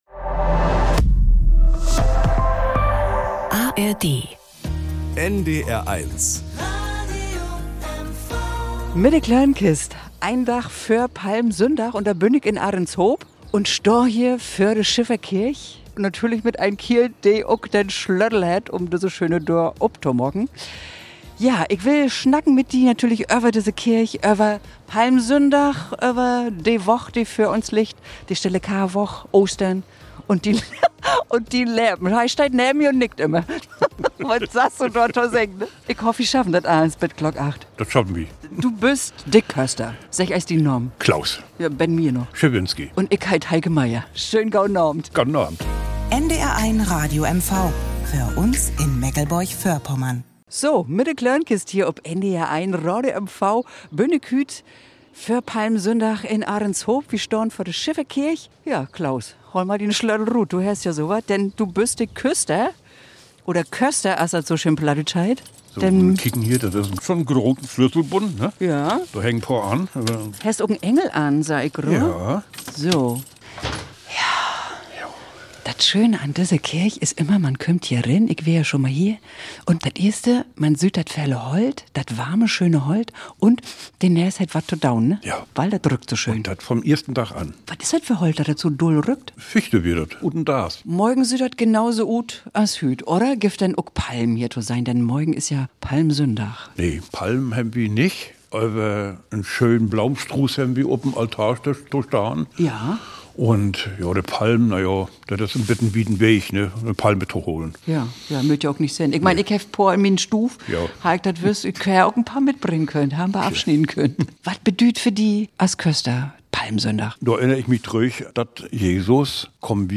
Nachrichten aus Mecklenburg-Vorpommern - 09.07.2025